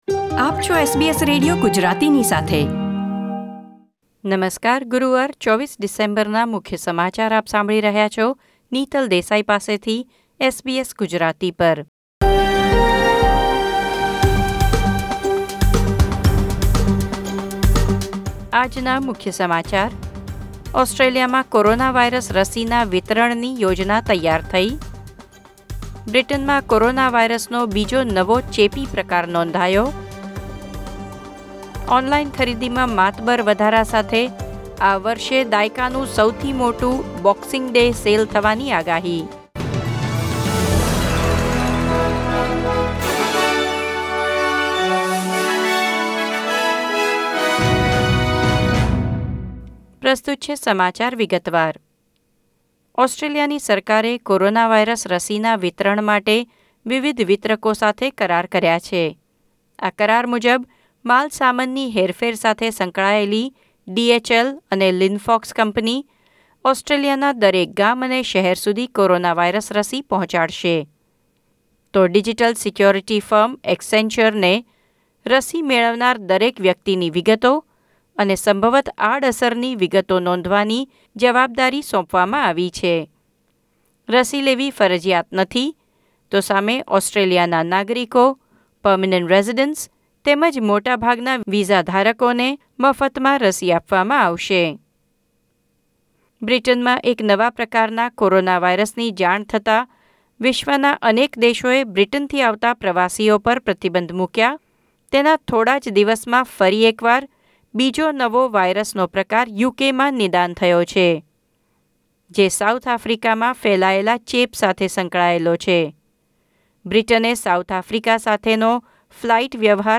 SBS Gujarati News Bulletin 24 December 2020